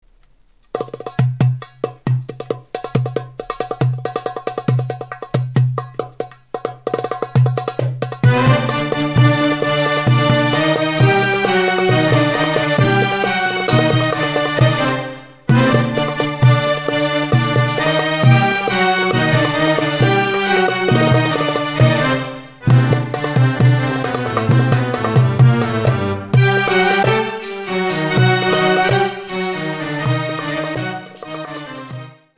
TAMRAHENNA - Lots of stops.